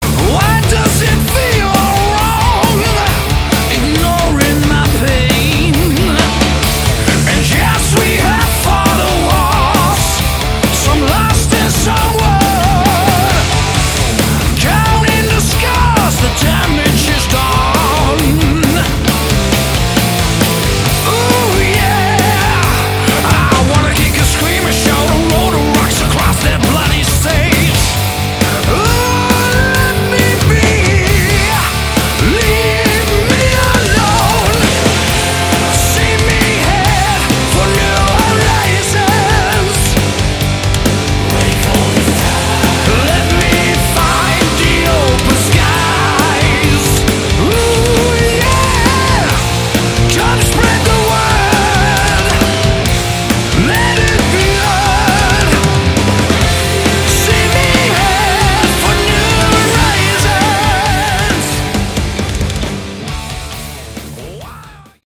Category: Melodic Metal
guitars
vocals
bass
drums